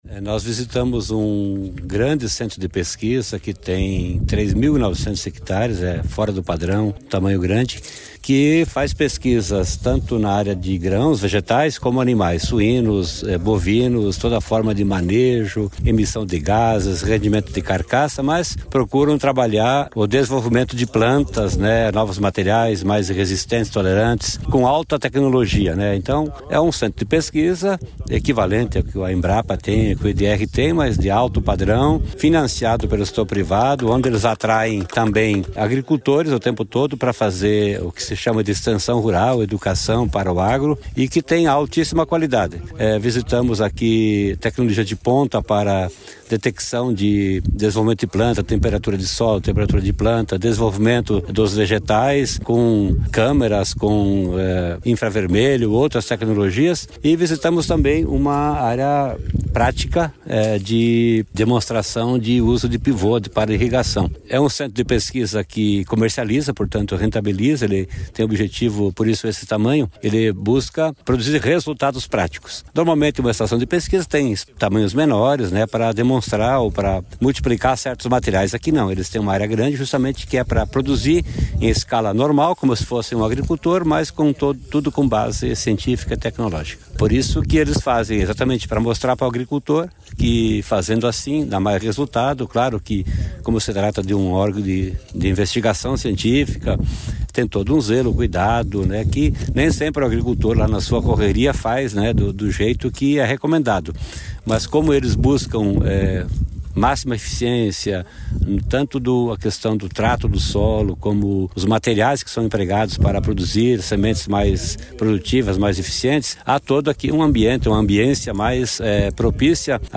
Sonora do secretário da Agricultura e do Abastecimento, Norberto Ortigara, sobre os projetos de extensão rural do Nebraska durante a missão internacional